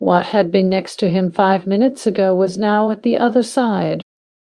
Text-to-Speech
more clones